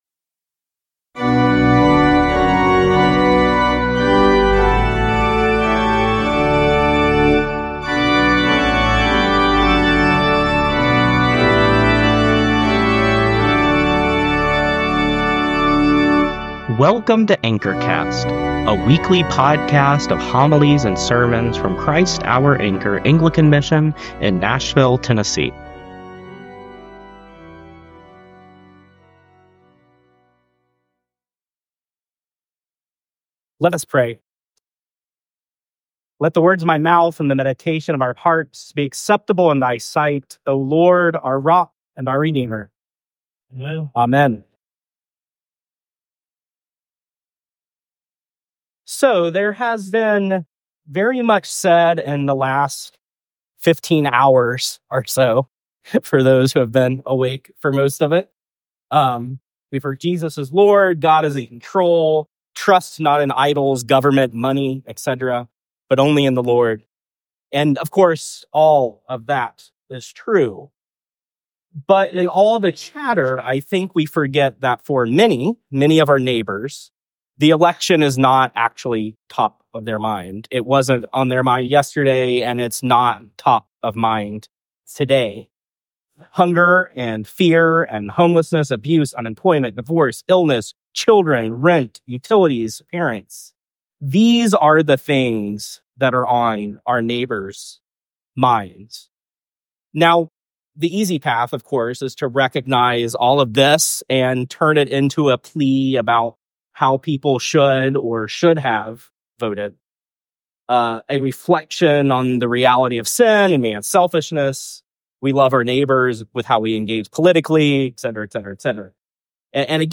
sermon-2024-11-07.mp3